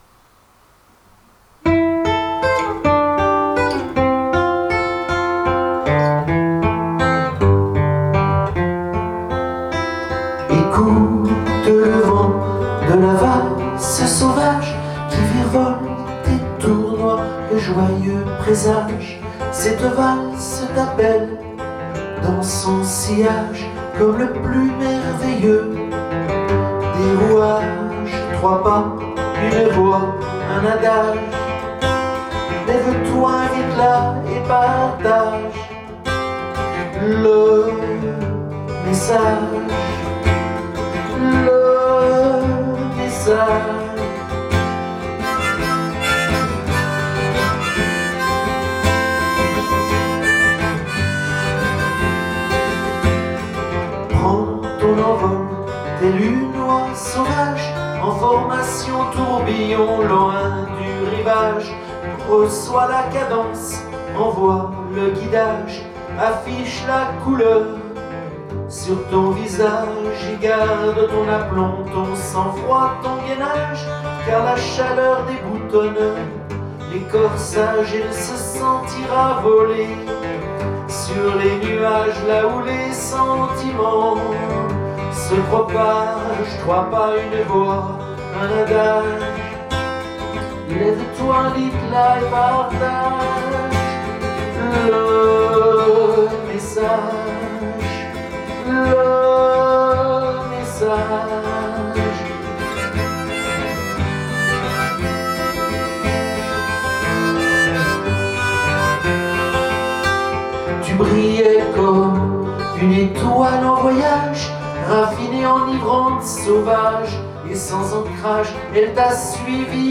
une valse grisante et sauvage, un message enjoué.